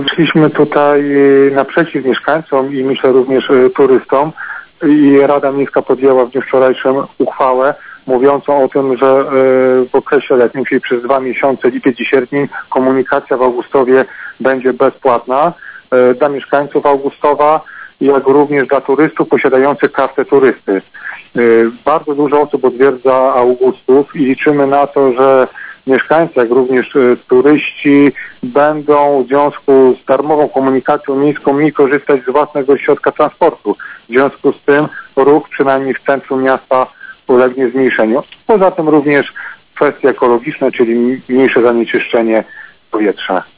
Szczegóły przedstawił Wojciech Walulik, burmistrz miasta.
Wojciech-Walulik-burmistrz-Augustowa-o-darmowej-komunikacji-miejskiej.mp3